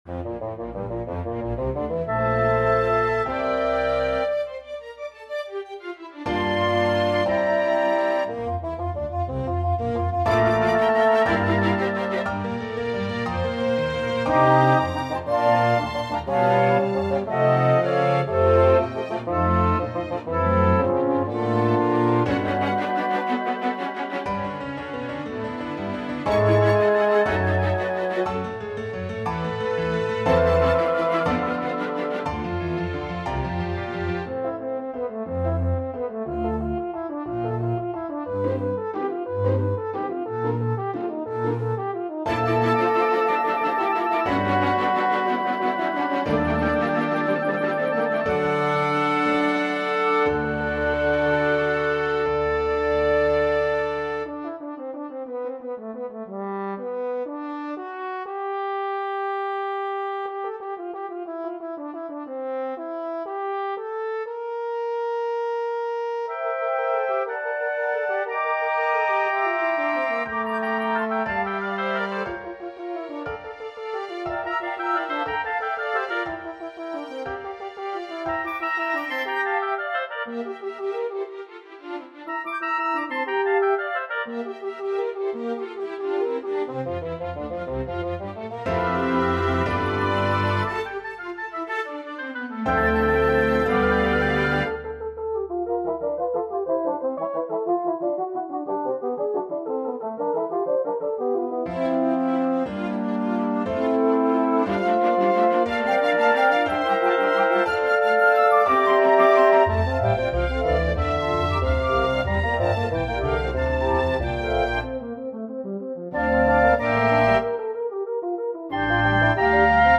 Demo 4: Allegro